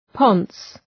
Προφορά
{pɒns}